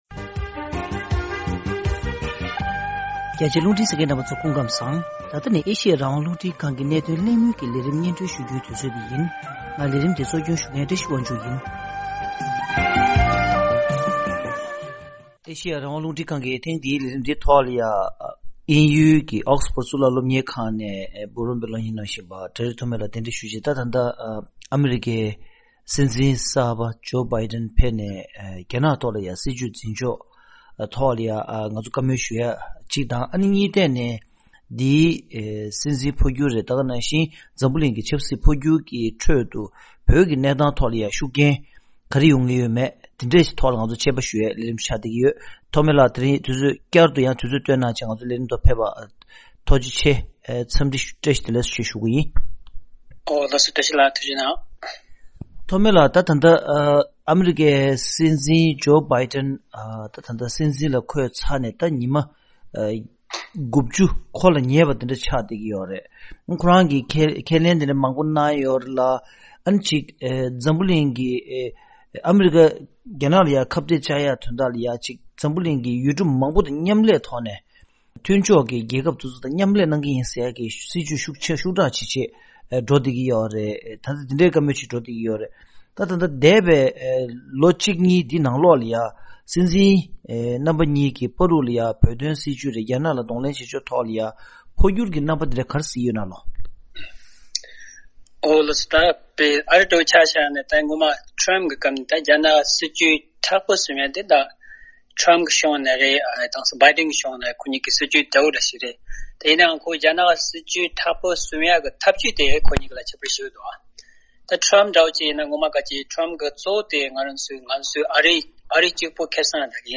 གནད་དོན་གླེང་མོལ